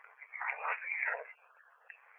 EVPs
I Love You.wav